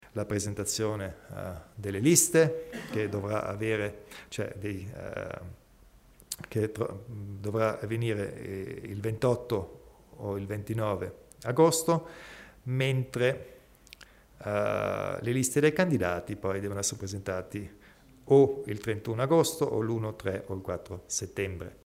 Il Presidente Kompatscher specifica le date e gli appuntamenti in vista delle elezioni provinciali